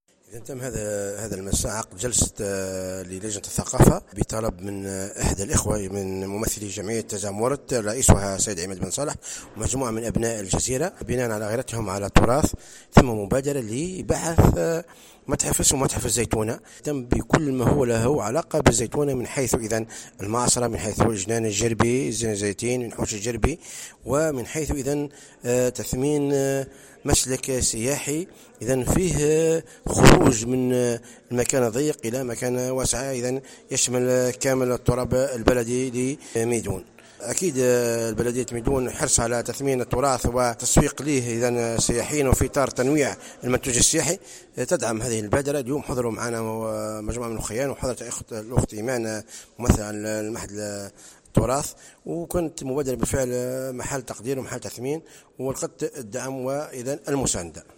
العضو بالمجلس البلدي كمال كرونة تحدث باكثر تفاصيل […]